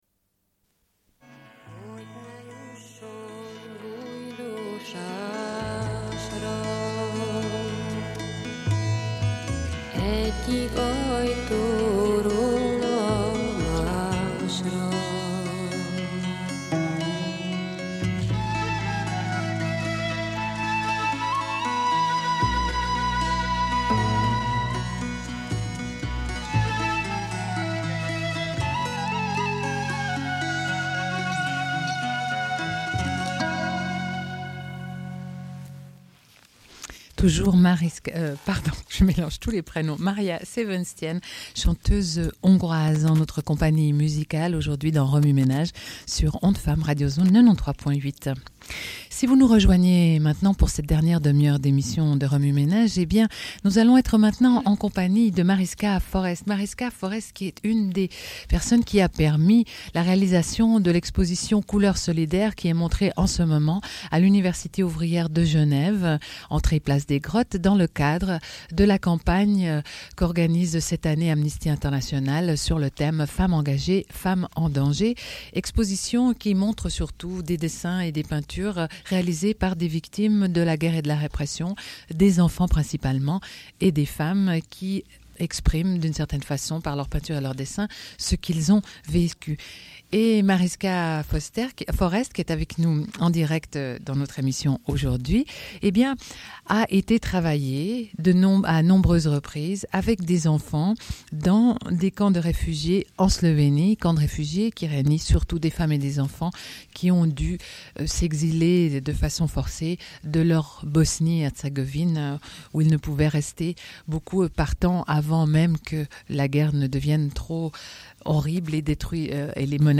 La campagne présente notamment l'exposition « Couleurs solidaires » à l'UOG é Genève, présentant des dessins et peintures de femmes et d'enfants réfugié·es. Diffusion d'un entretien avec trois participantes à la campagne et victimes de guerre
Une cassette audio, face A
Radio